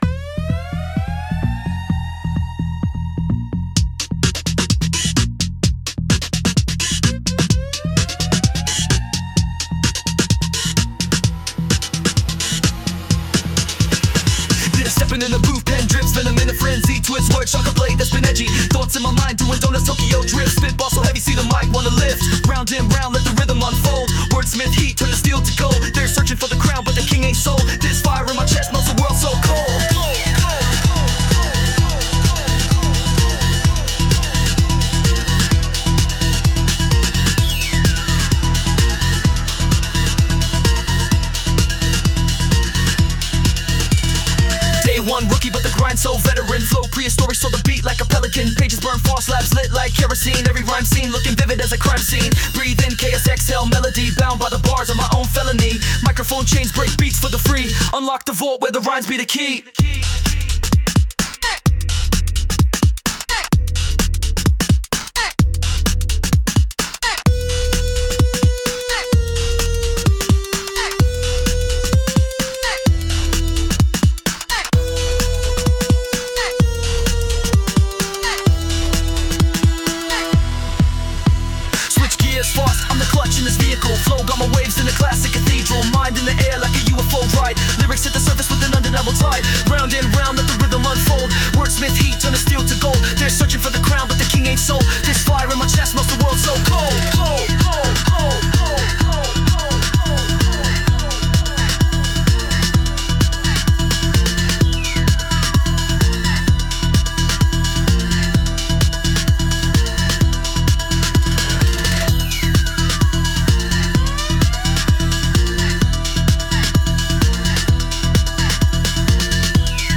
I tried rap arrangement of the boss battle music